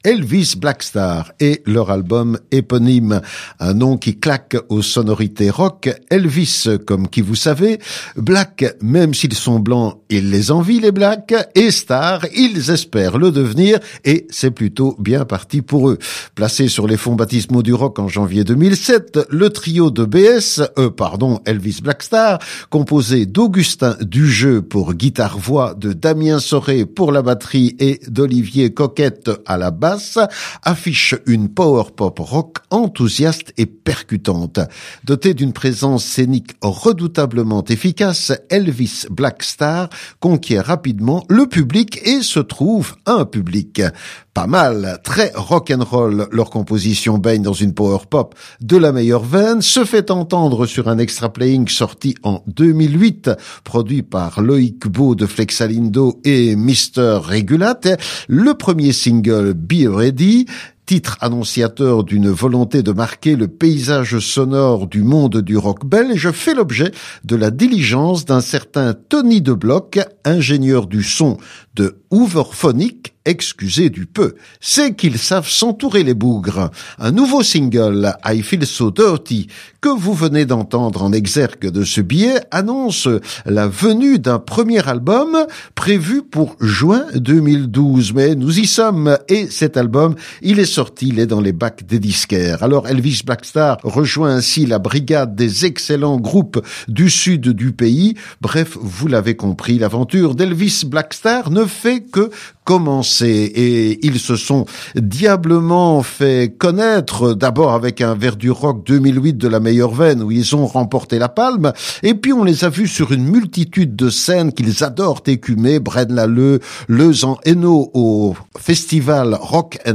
Leur album est bourré de hits pop, directs et puissants.
Une énergie non feinte et sans faille.
Du pop-rock’n’roll pur jus !